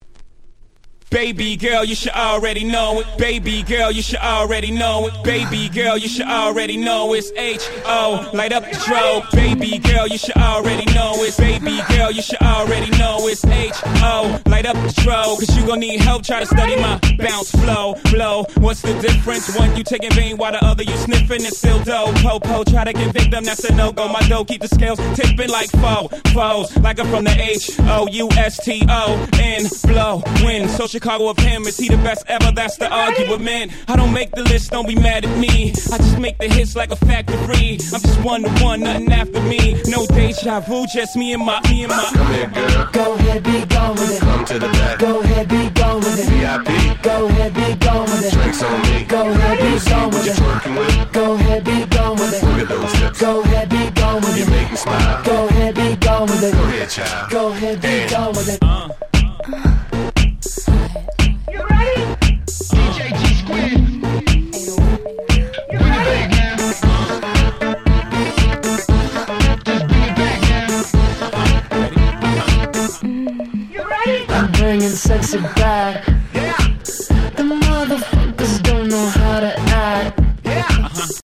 06' Very Nice R&B Remix !!